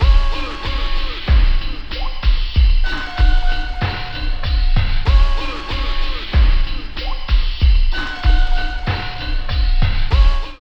08.4 LOOP.wav